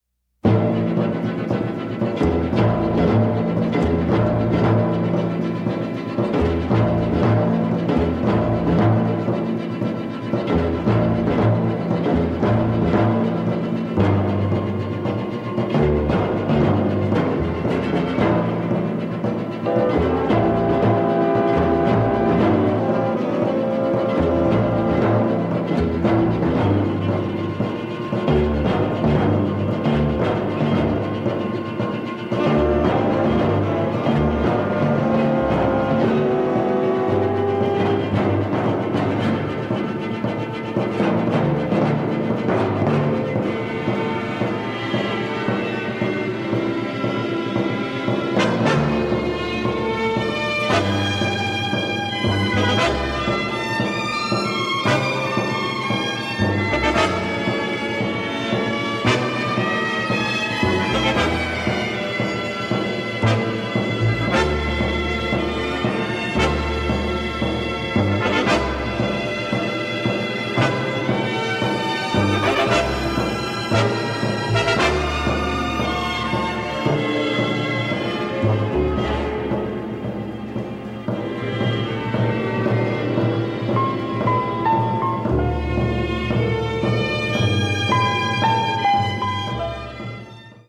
the melodies are stunningly beautiful.